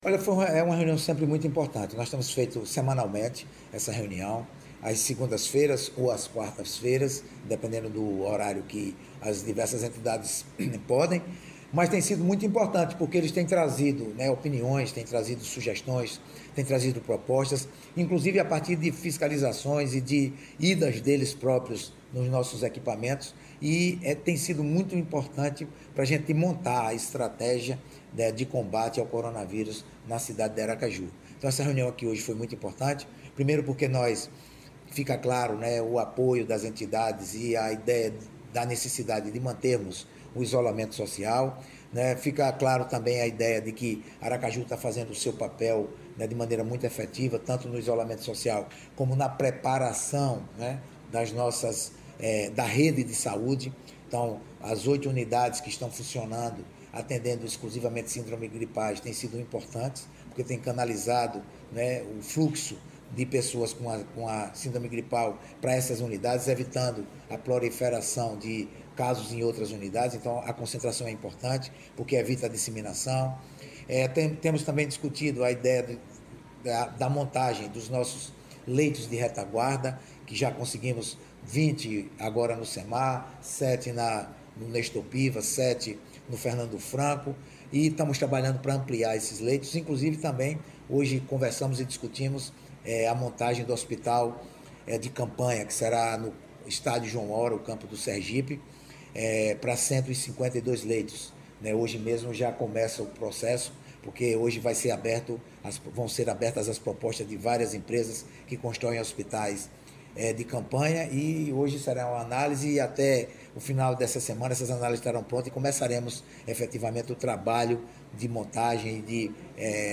Prefeito Edvaldo Nogueira anuncia a montagem de um Hospital de Campanha
Audio_prefeito.mp3